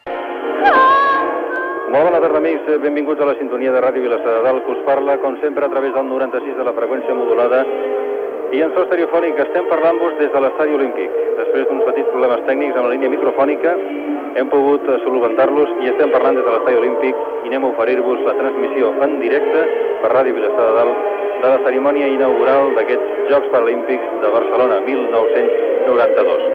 Inici de la transmissió de la cerimònia inaugural dels Jocs Paralimpics Barcelona 1992.
Informatiu